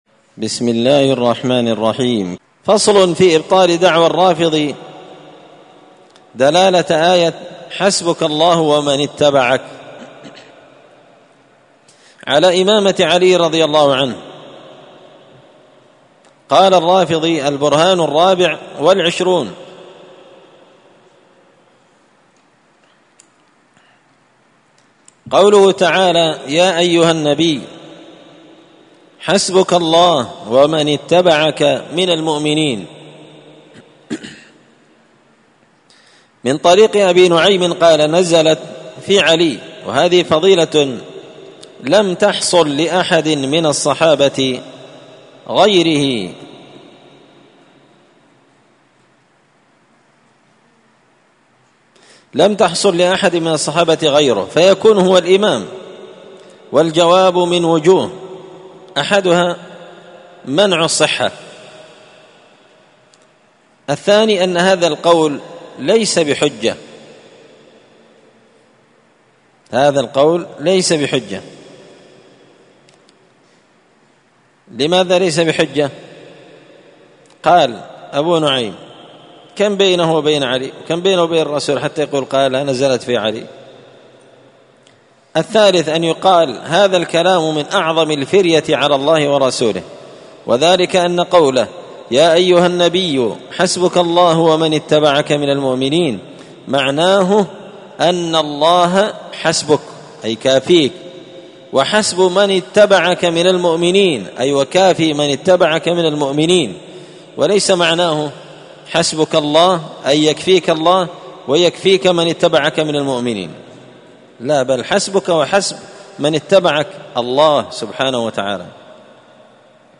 الأثنين 12 صفر 1445 هــــ | الدروس، دروس الردود، مختصر منهاج السنة النبوية لشيخ الإسلام ابن تيمية | شارك بتعليقك | 66 المشاهدات
مسجد الفرقان قشن_المهرة_اليمن